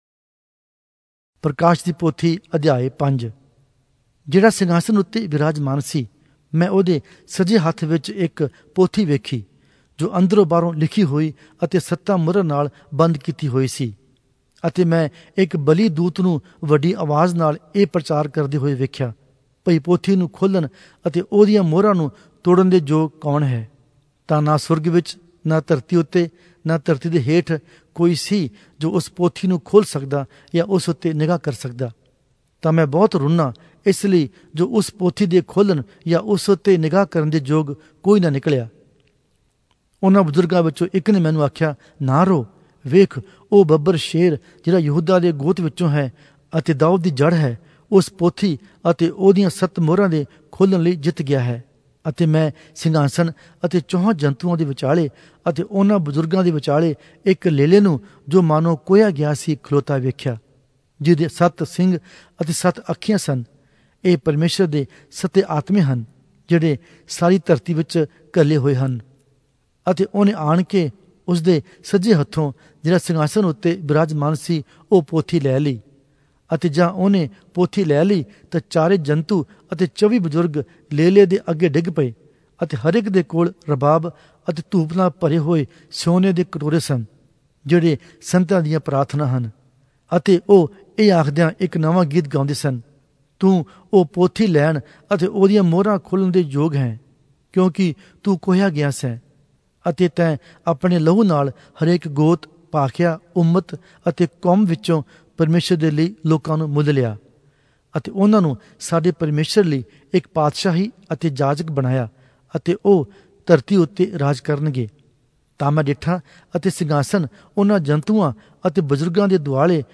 Oriya Audio Bible - Revelation 9 in Rcta bible version